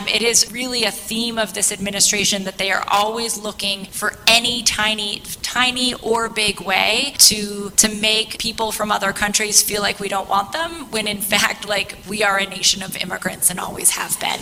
The effects of both the recently passed Trump spending bill and the increase in ICE activity against illegal immigrants is something that concerns Maryland’s State Comptroller.  Recently, at a town hall hosted by Congresswoman April McClain Delaney, Brooke Lierman was asked what she thought was the worst part of the bill for Marylanders and she pointed to immigration…